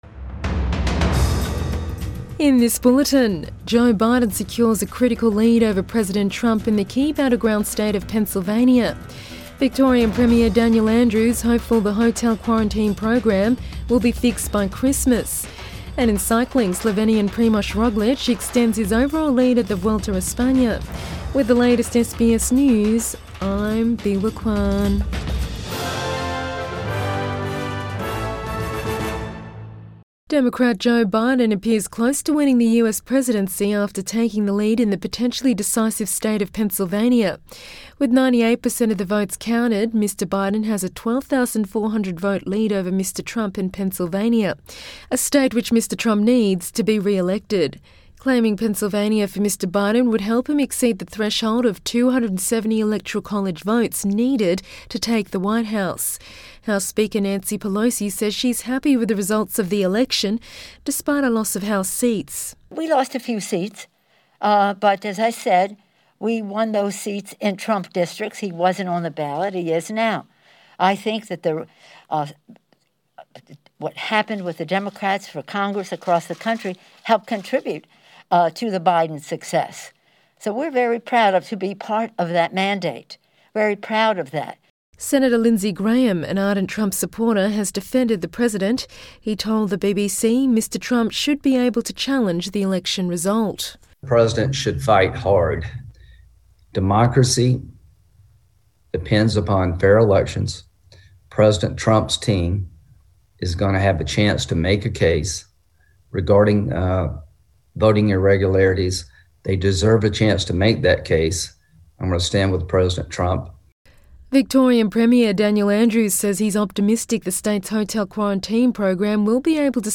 AM bulletin 7 November 2020